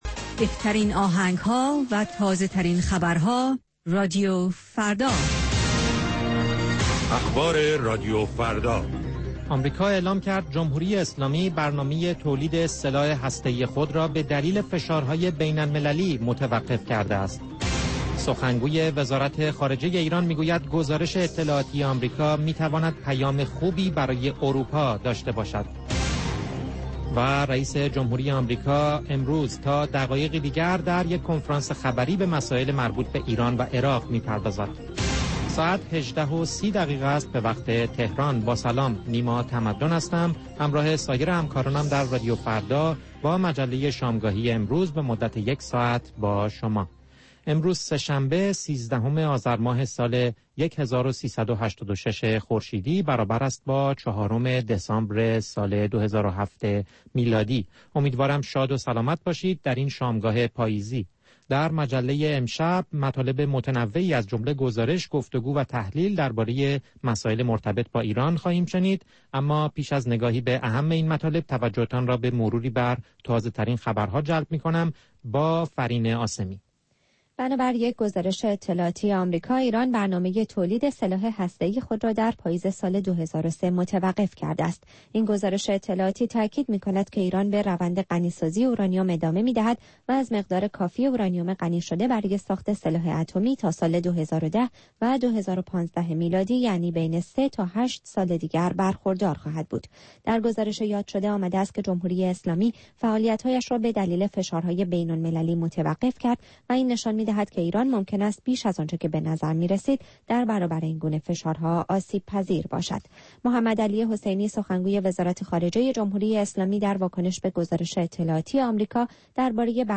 مجموعه ای متنوع از آنچه در طول روز در سراسر جهان اتفاق افناده است. در نیم ساعات مجله شامگاهی رادیو فردا، آخرین خبرها و تازه ترین گزارش های تهیه کنندگان این رادیو فردا پخش خواهند شد.